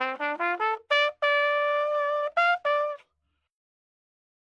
MELLOW TRUMPET (260 Kb) NEW